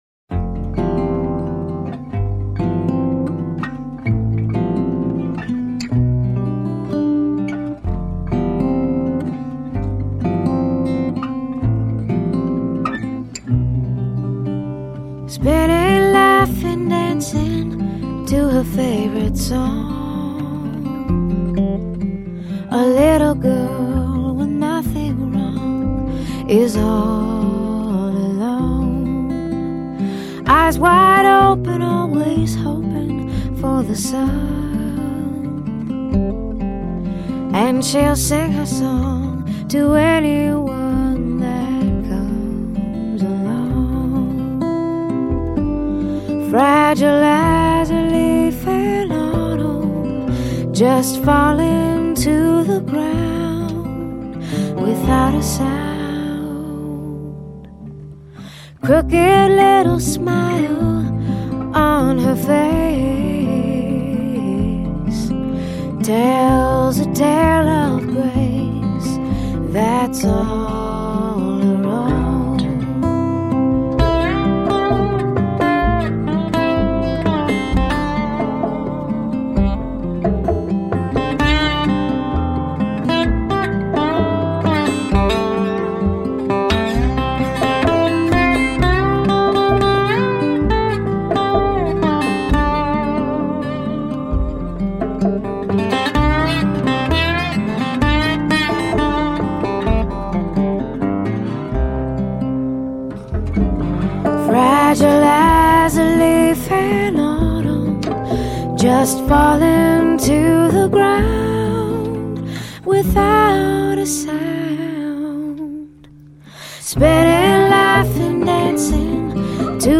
音乐类型：爵士乐
慵慵懒懒的爵士轻乐,放松心思,一杯咖啡,作沙发聆听....